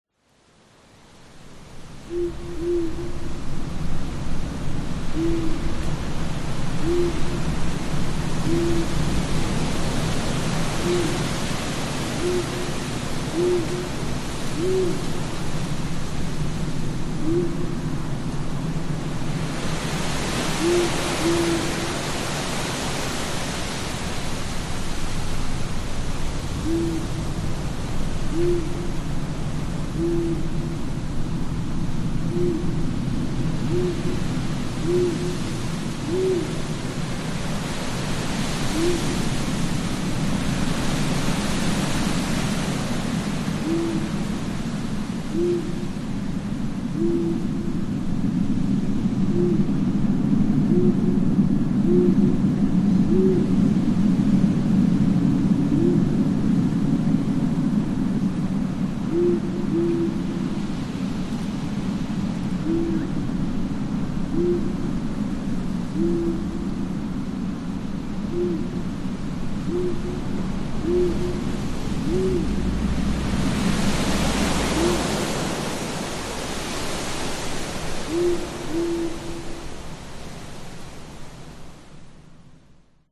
Тихие звуки кладбища летней ночью: вой ветра и крик совы